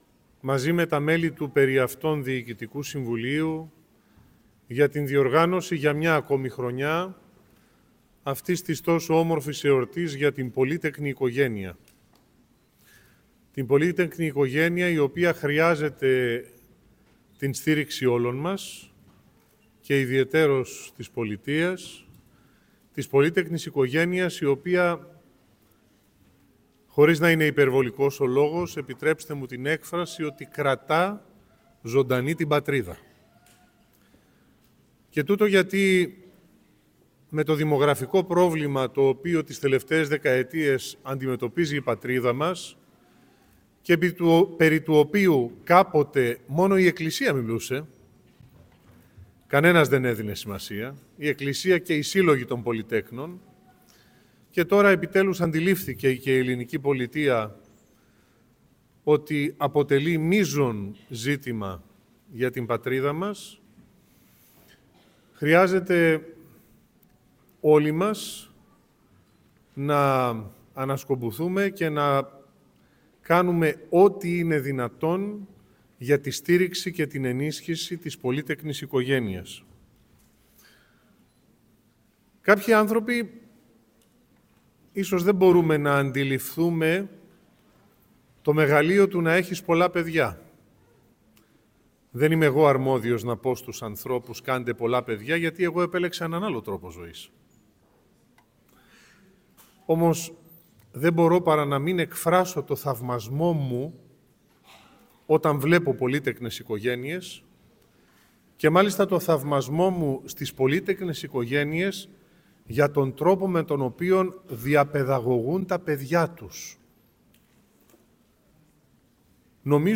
Σε μια ιδιαίτερα σημαντική εκδήλωση που πραγματοποιήθηκε σήμερα, Κυριακή 2 Μαρτίου, στην Αίθουσα Τελετών του Αριστοτελείου Πανεπιστημίου Θεσσαλονίκης, ο Κεντρικός Σύλλογος Πολυτέκνων Νομού Θεσσαλονίκης «Άγιοι Πάντες» βράβευσε περίπου 800 αριστούχους μαθητές Γυμνασίου και Λυκείου από πολύτεκνες και υπερπολύτεκνες οικογένειες, αναγνωρίζοντας τις επιδόσεις και την προσπάθειά τους.
Στην εκδήλωση παρευρέθηκε ο Παναγιώτατος Μητροπολίτης Θεσσαλονίκης κ. Φιλόθεος, ο οποίος στηρίζει ενεργά τις πολύτεκνες οικογένειες και την πρόοδο των νέων.